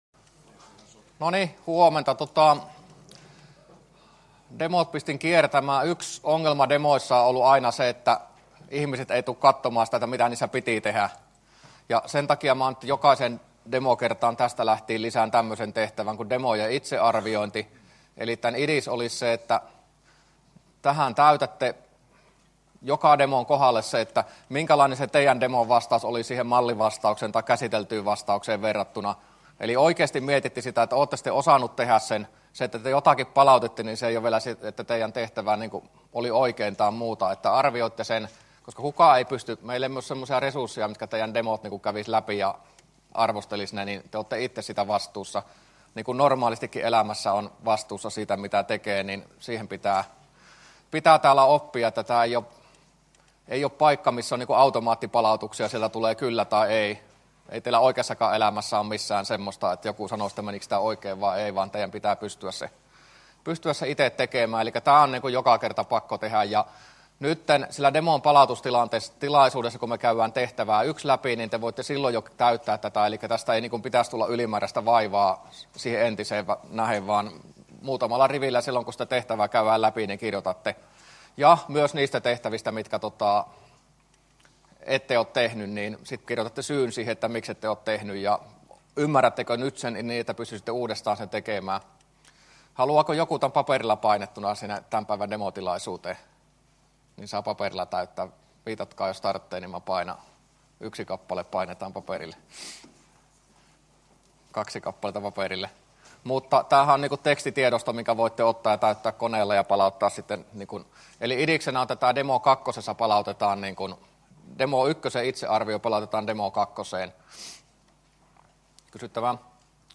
luento03a